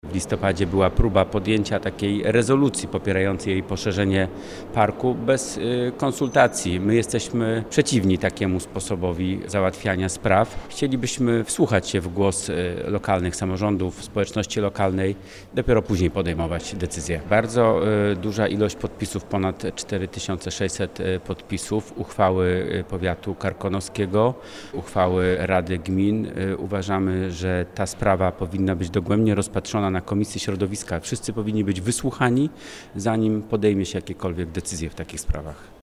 Zdaniem Piotra Karwana, szefa klubu PiS w Sejmiku Województwa Dolnośląskiego, taki projekt wymaga konsultacji.